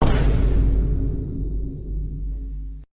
sfx_shock.ogg